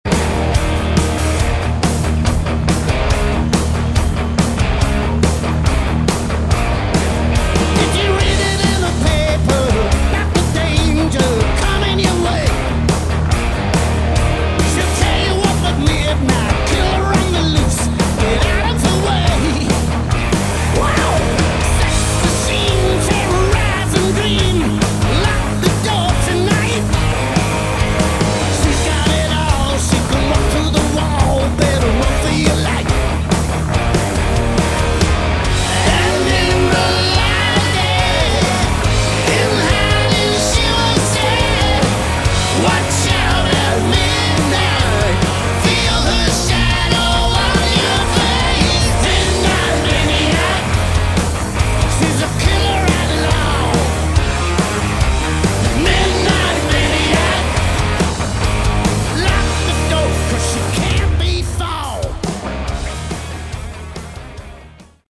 Category: Hard Rock
Lead Vocals
backing vocals
bass
drums
guitar
keyboards
Re-issued in 2024 with a bonus live CD (Disc 2 below)